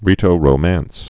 (rētō-rō-măns)